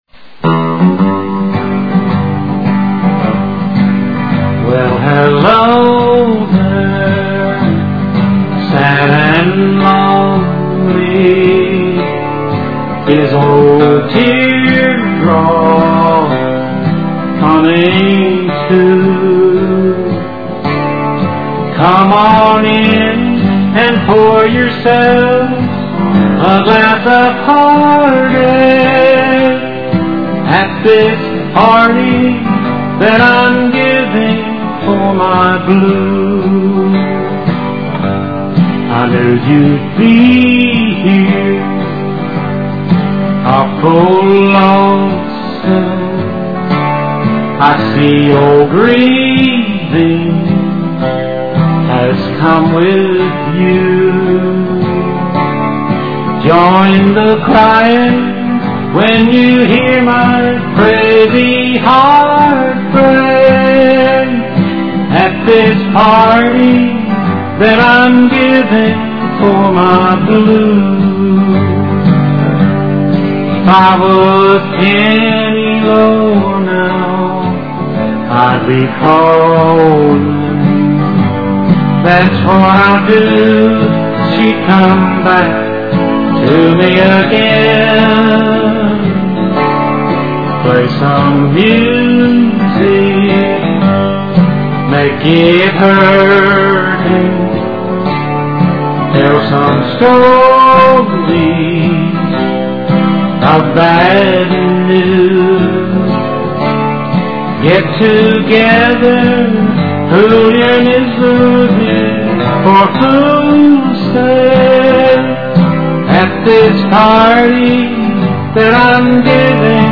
A Tribute To Old Time Country Music
plays some very impressive guitar along with his singing